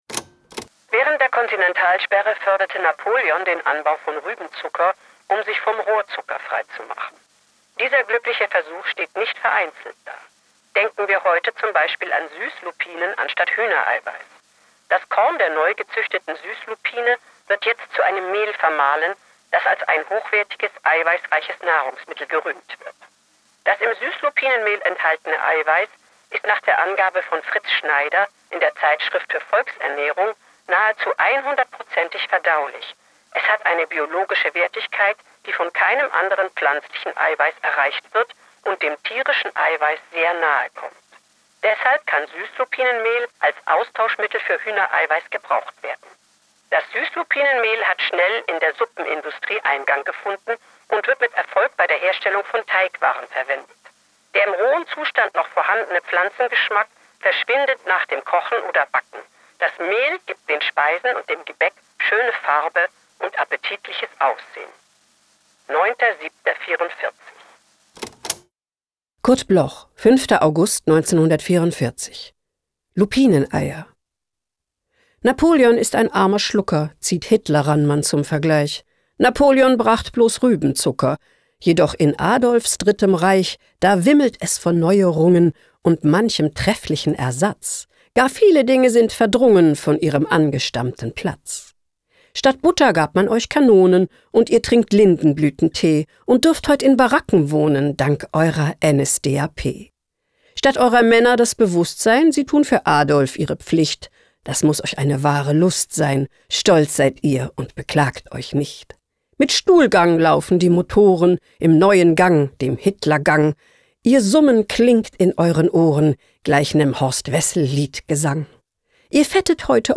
Johanna Gastdorf (* 1959) is een Duitse actrice.